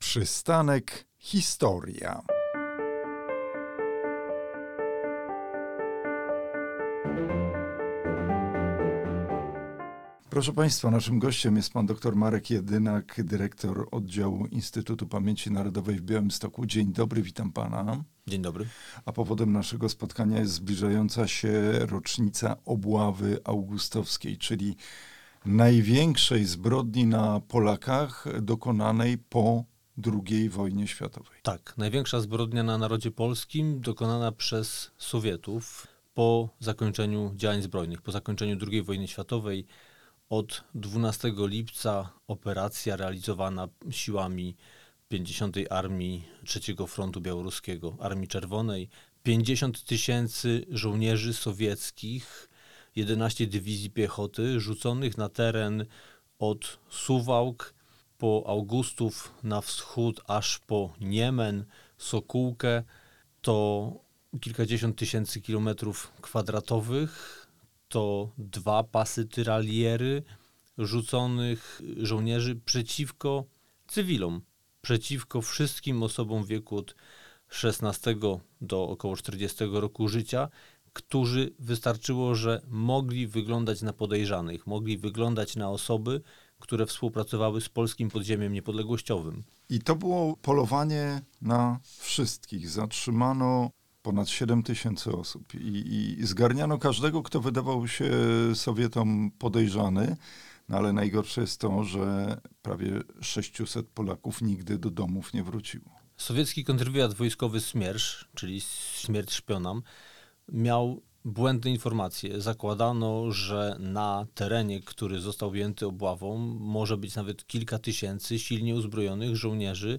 Obława Augustowska. Zbrodnia odkrywana przez lata. Rozmowa